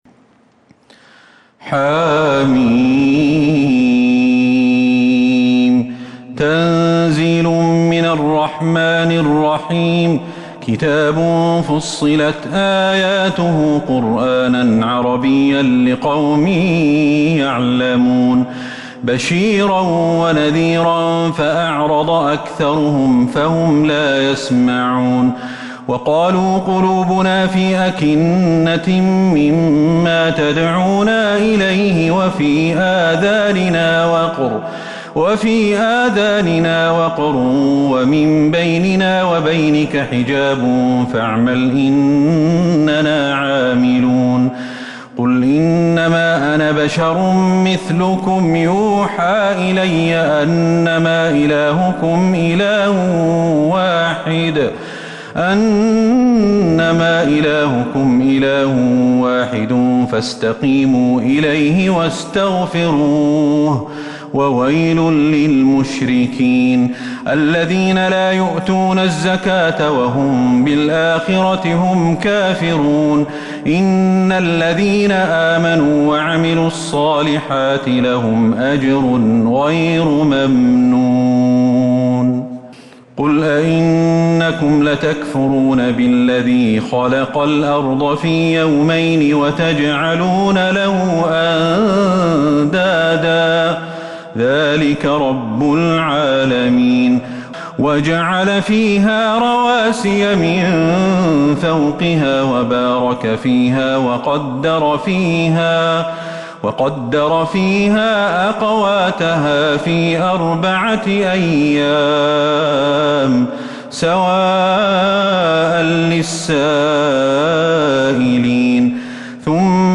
سورة فصلت Surat Fussilat من تراويح المسجد النبوي 1442هـ > مصحف تراويح الحرم النبوي عام 1442هـ > المصحف - تلاوات الحرمين